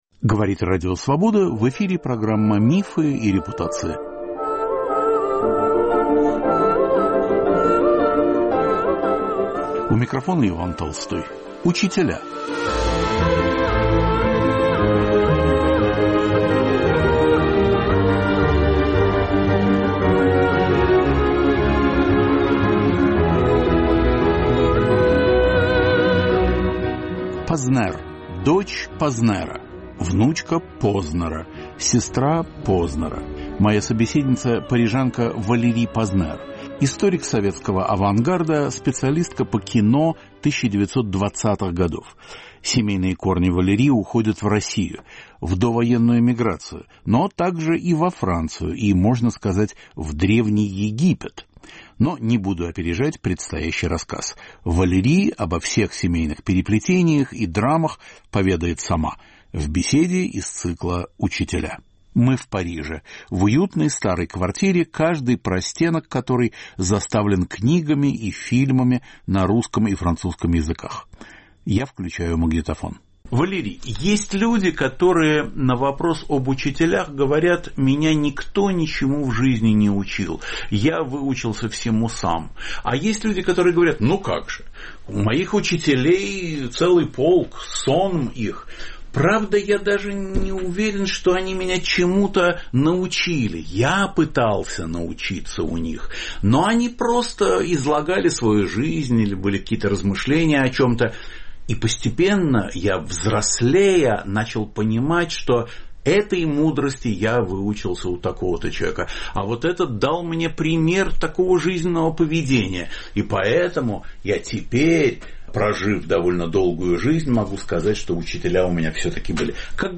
Беседа с французской исследовательницей советского кино и художественного авангарда.